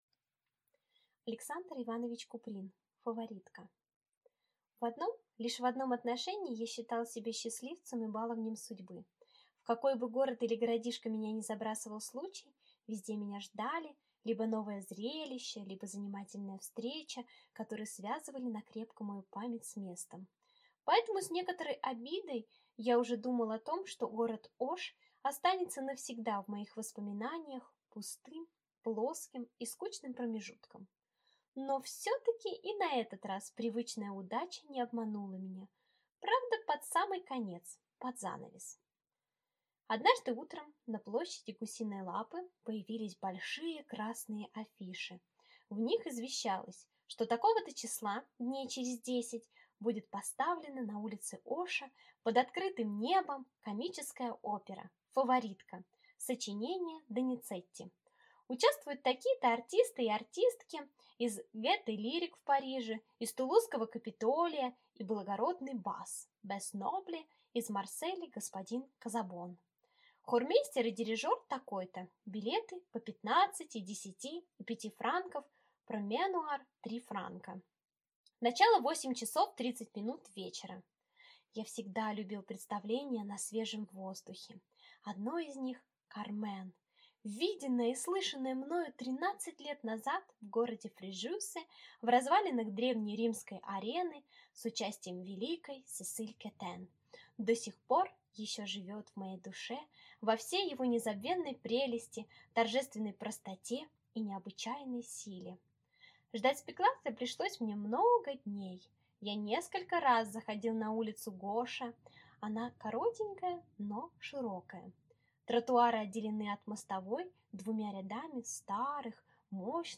Аудиокнига «Фаворитка» | Библиотека аудиокниг